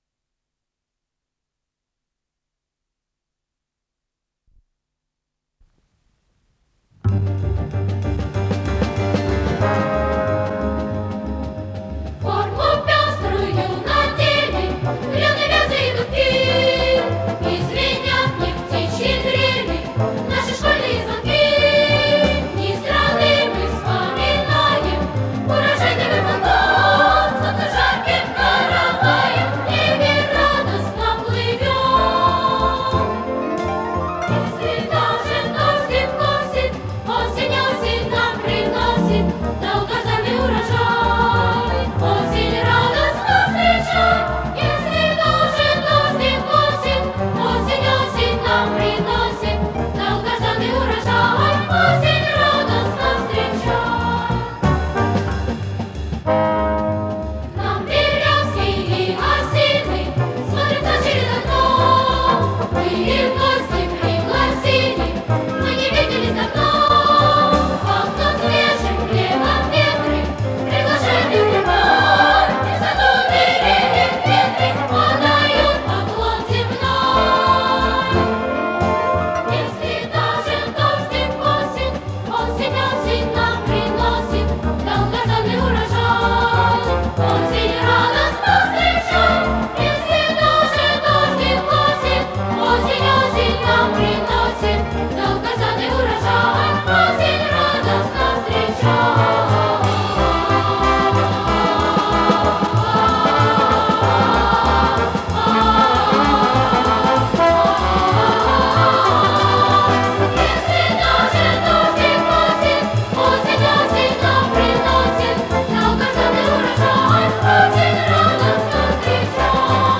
с профессиональной магнитной ленты